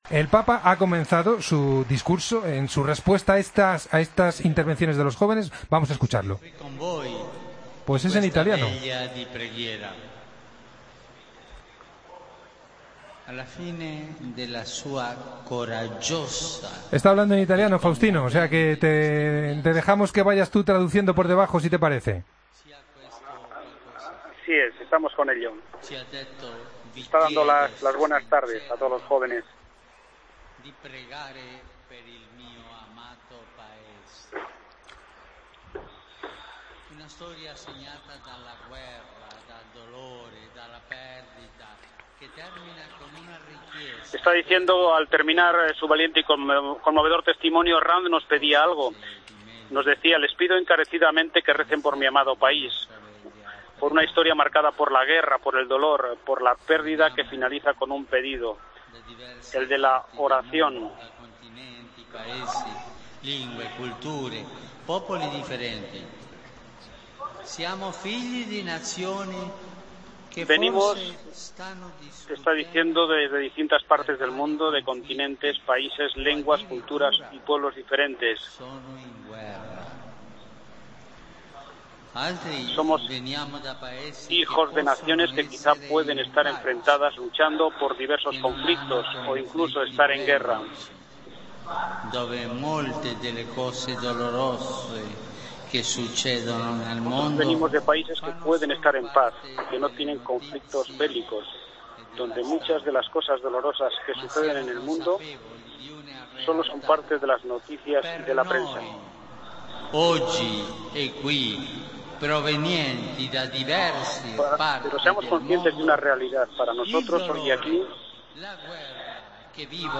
Escucha el discurso del Papa en la Vigilia con los jóvenes de la JMJ